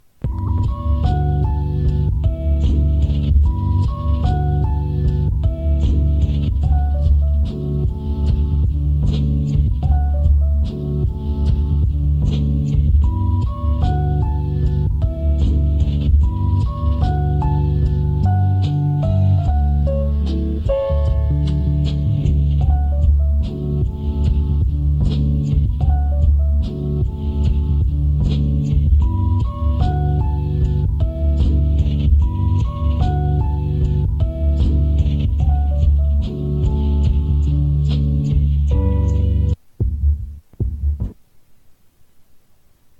Loose beats